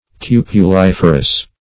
Search Result for " cupuliferous" : The Collaborative International Dictionary of English v.0.48: Cupuliferous \Cu`pu*lif"er*ous\ (k?`p?-l?f"?r-?s), a. [Cupule + -ferous: cf. F. cupulif[`e]re.]